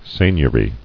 [seign·ior·y]